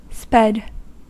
Ääntäminen
Synonyymit speeded Ääntäminen : IPA : /ˈspɛd/ US : IPA : [ˈspɛd] Haettu sana löytyi näillä lähdekielillä: englanti Sped on sanan speed partisiipin perfekti.